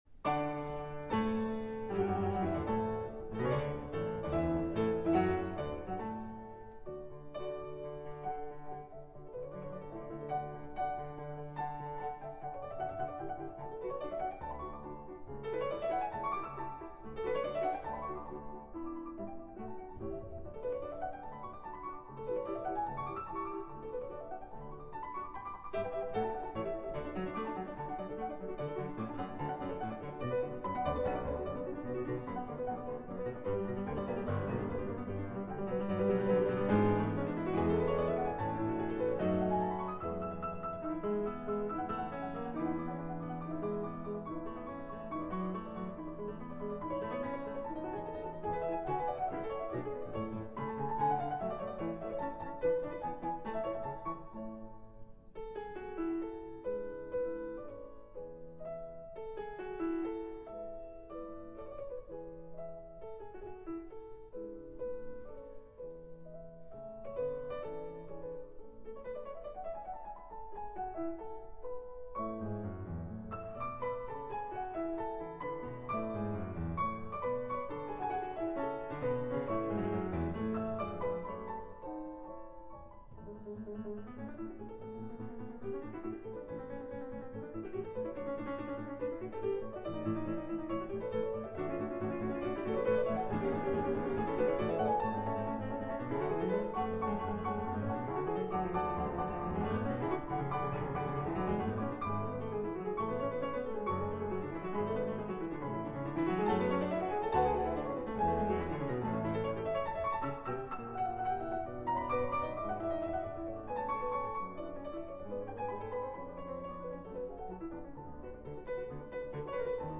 The first 8 minutes of the Sonata for Two Pianos in D Major, K448 has been proven clinically to reduce seizures and IED frequencies after long term exposure.
Affirmations incorporated!